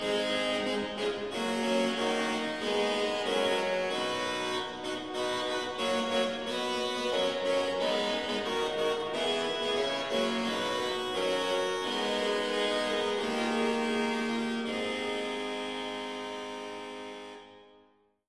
Leitung und Posaune